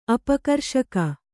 ♪ apakarṣaka